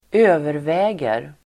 Uttal: [²'ö:vervä:ger]